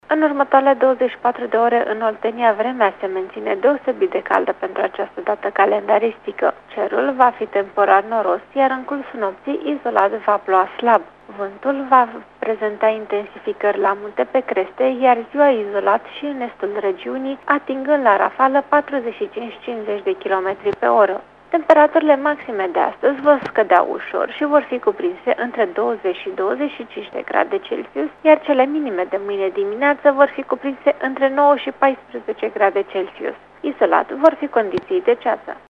Prognoza meteo pentru 1 noiembrie
1-NOIEMBRIE-PROGNOZA-AM.mp3